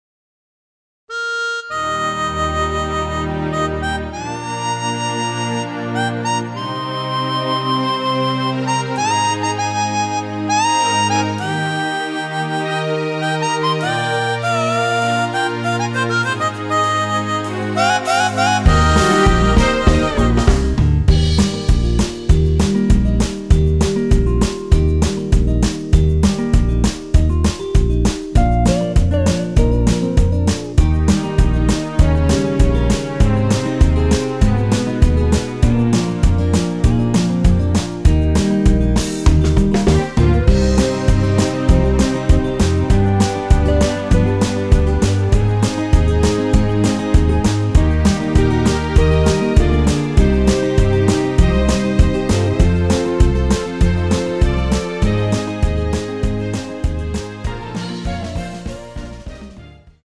전주가 길어서 8마디로 편곡 하였으며
원키에서(+3)올린 (1절+후렴)으로 진행되는MR입니다.
Eb
앞부분30초, 뒷부분30초씩 편집해서 올려 드리고 있습니다.